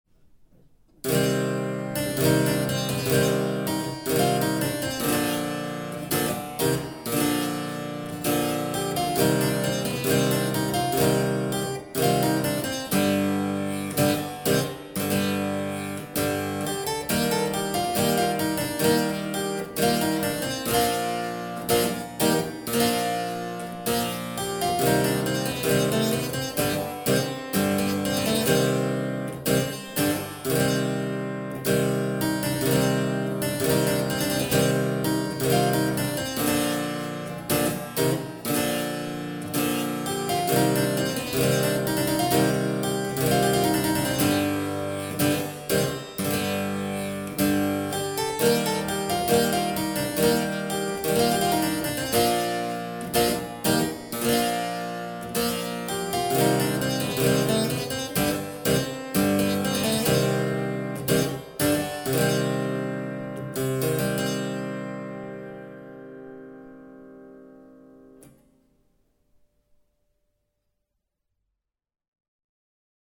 Clavicembalo
CLAVICEMBALO-PassoEMezzoAntico1.mp3